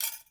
ItemDrag.wav